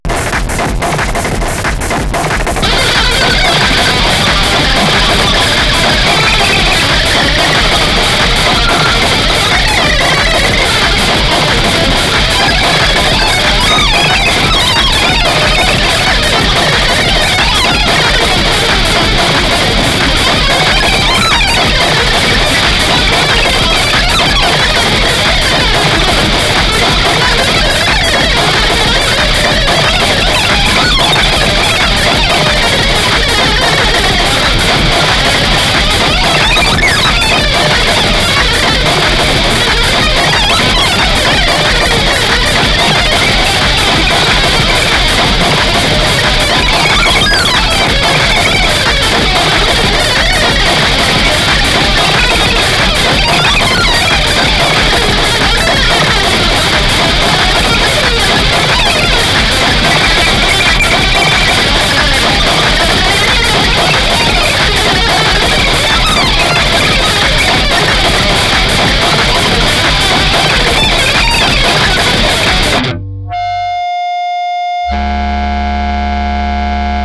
ファン待望の、ハードコア・テクノとハードコア・インプロヴィゼーションが融合した、
圧倒的な高エネルギー、高密度、高速度、高圧力、高温度、高濃度、高純度、高硬度、高光度な